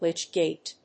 /lítʃgèɪt(米国英語)/